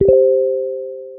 Telegram notification